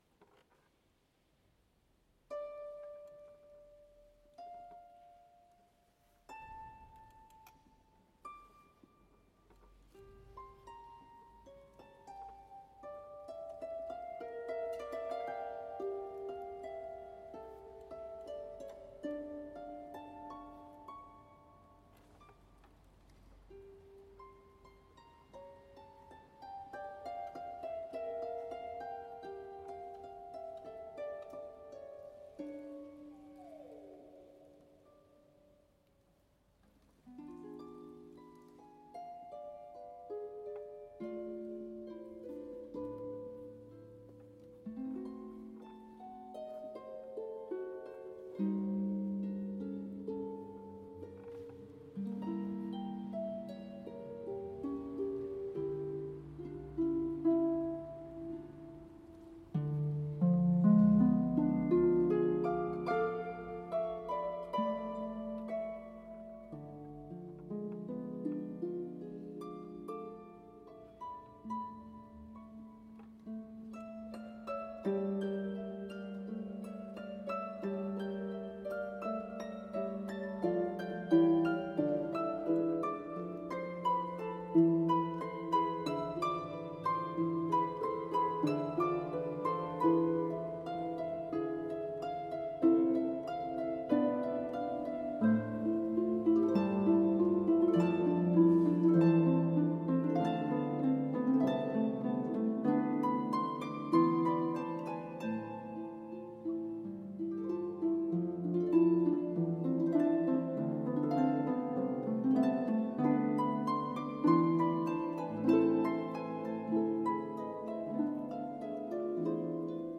Harp duo
for two pedal harps